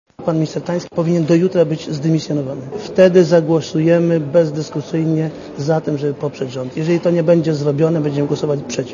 Dla Radia Zet mówi Wojciech Mojzesowicz (35 KB)